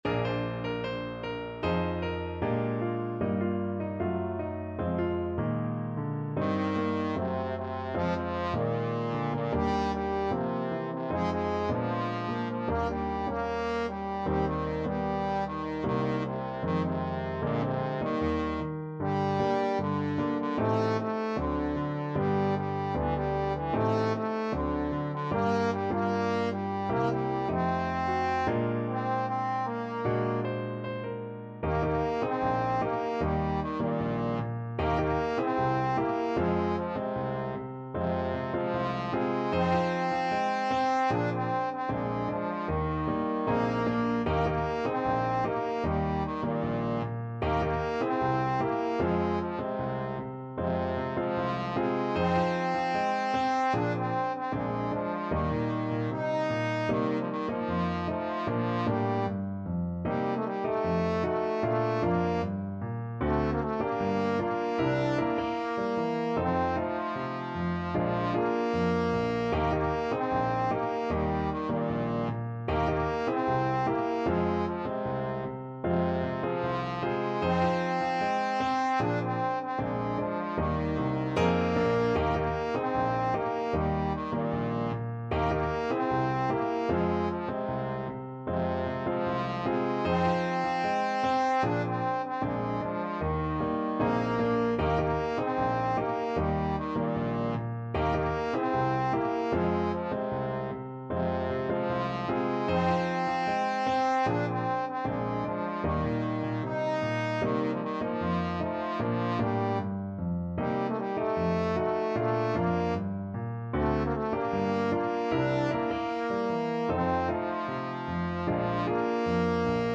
2/2 (View more 2/2 Music)
Bb3-Eb5
Pop (View more Pop Trombone Music)